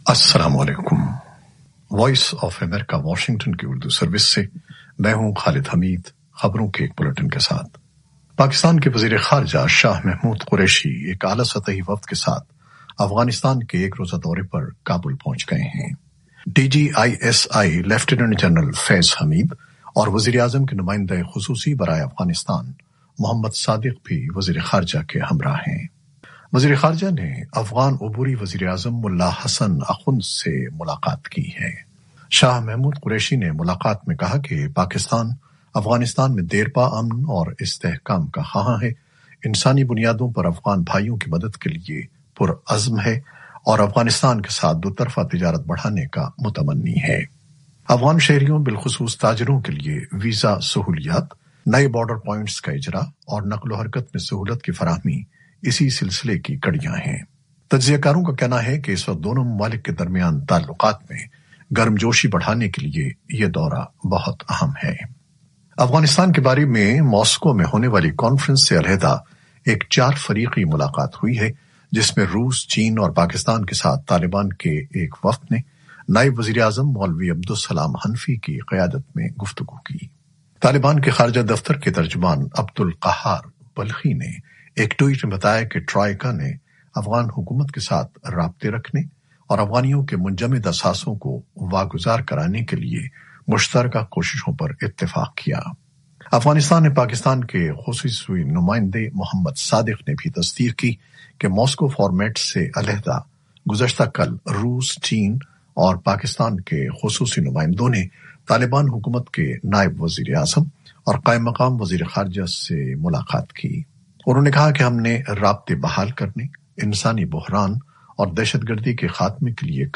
نیوز بلیٹن 2021-21-10